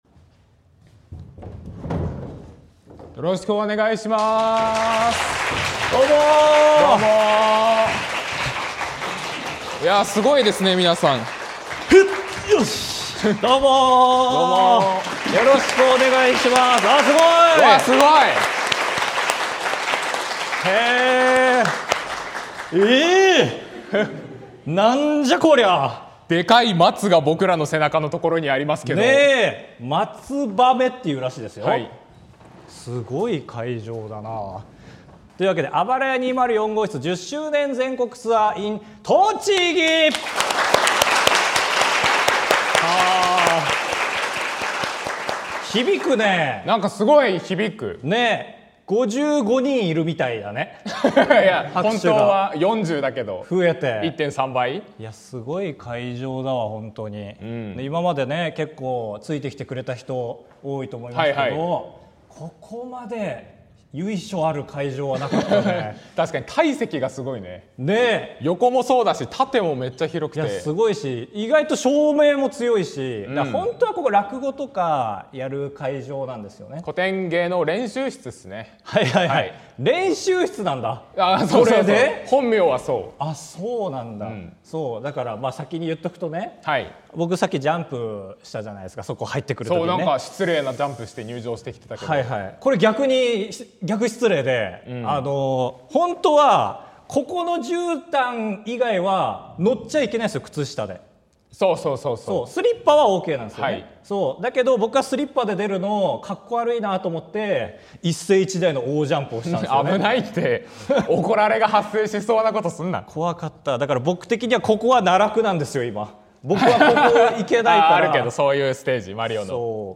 ▼素晴らしい松の木の前で収録 ▽ソフトクリーム機を仕込め ▼春風亭一門の罠 あばらや204号室Rは皆さんからの声をお待ちしています。